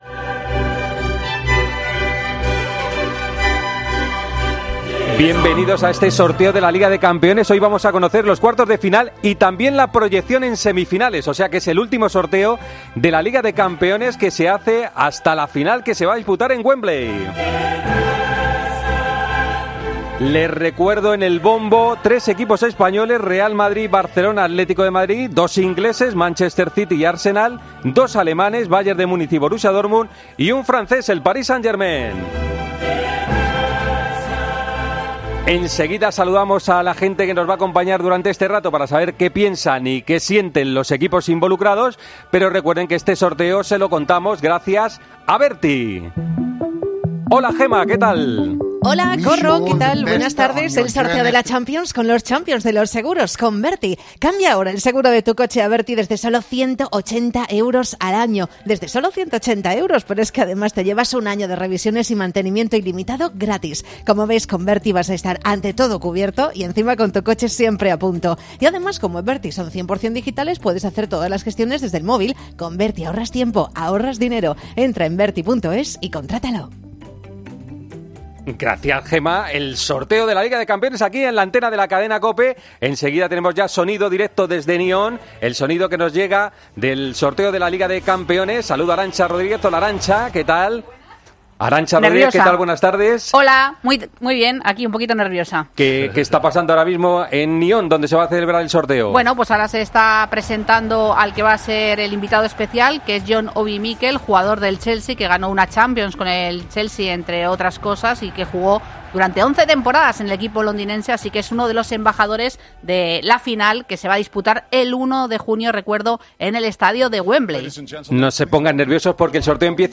AUDIO: Escucha la retransmisión en Tiempo de Juego del sorteo de cuartos y semifinales de la Liga de Campeones 2023/2024.